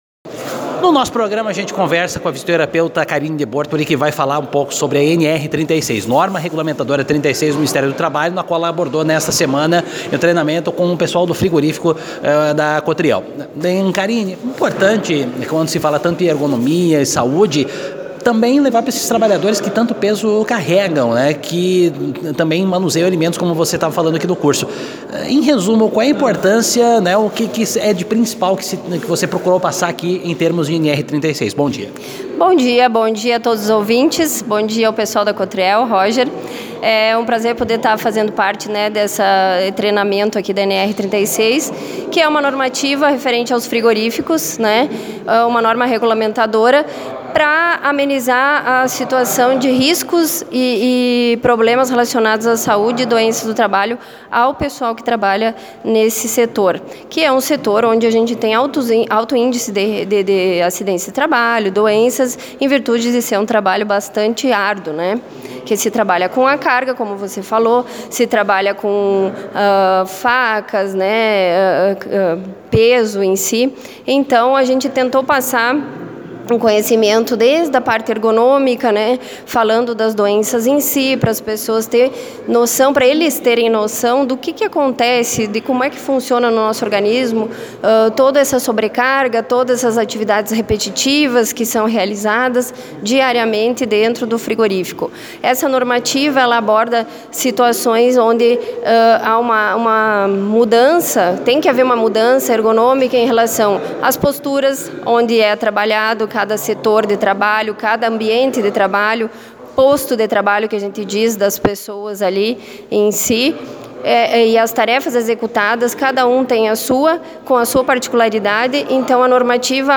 aqui a entrevista